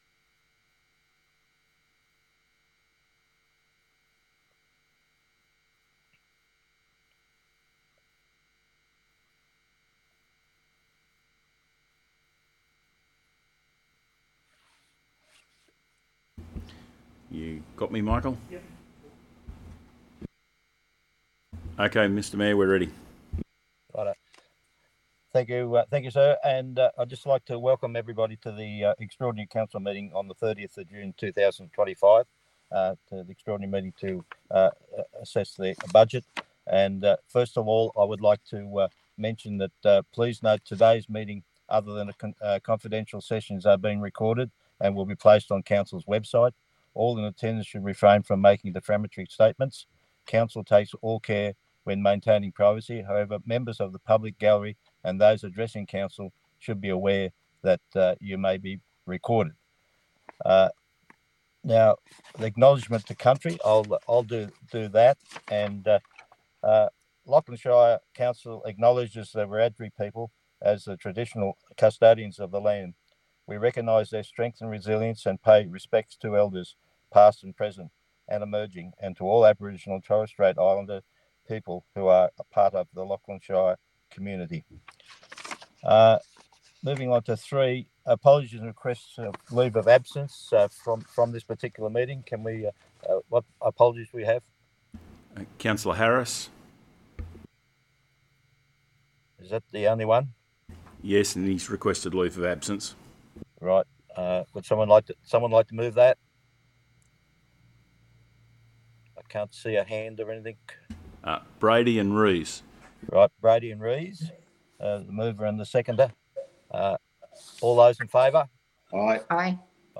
The June 2025 Extraordinary Meeting will be held in the Council Chambers at 2:00pm and is open to the public.